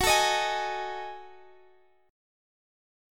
Gbm6add9 chord